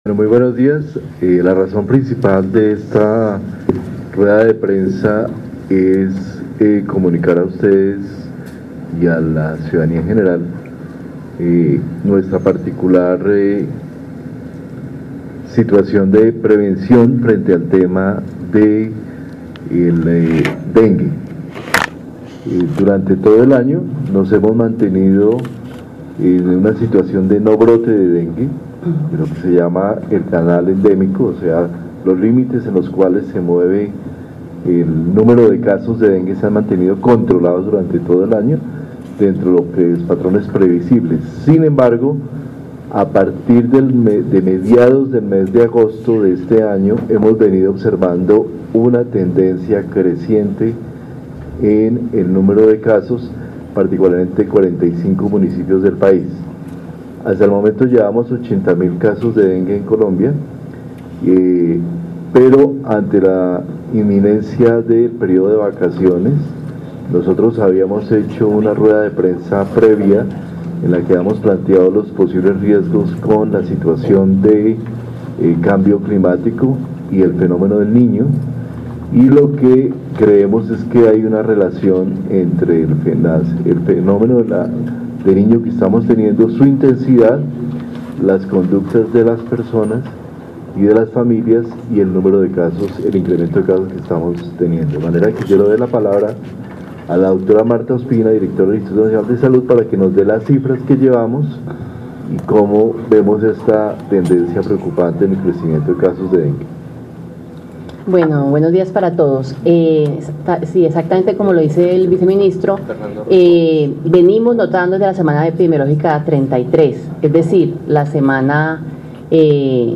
Audio: Intervención ViceSalud sobre aumento de  dengue por fenómeno del niño